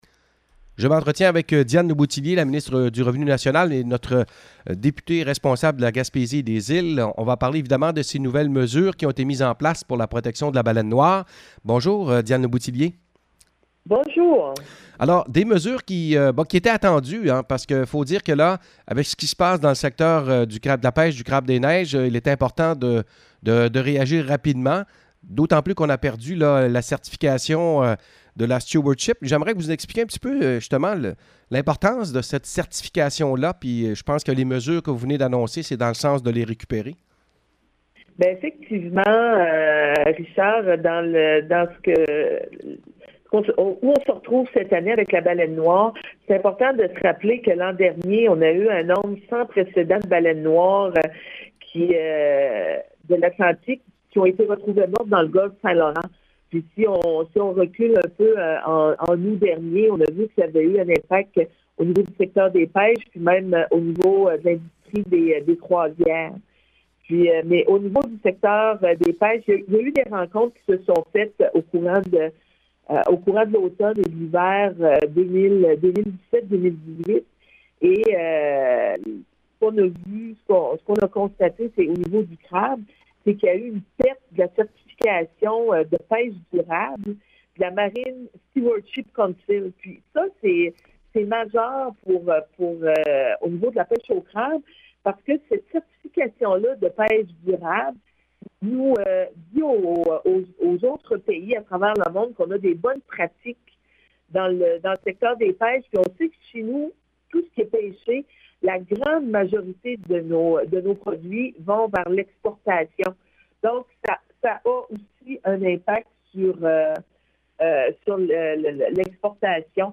Entrevue avec Diane Lebouthillier sur les mesures pour protéger les baleines: